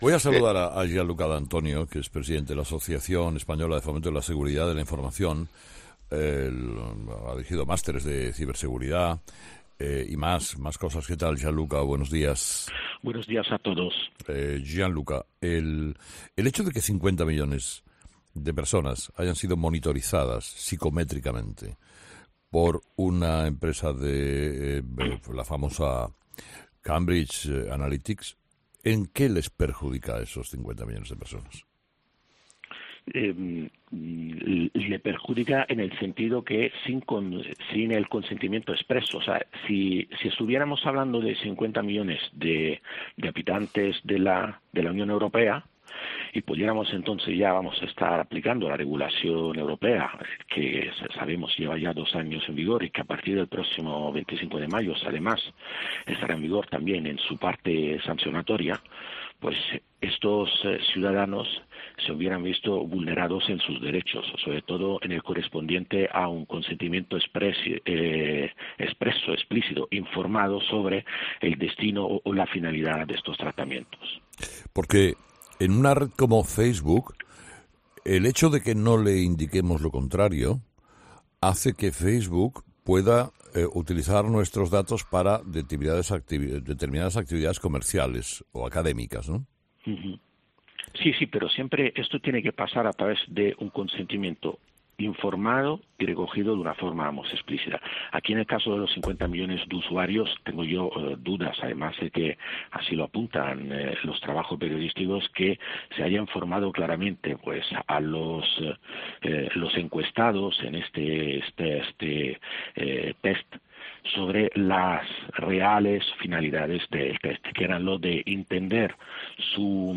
Un experto en ciberseguridad: "Facebook pudo violar derechos fundamentales"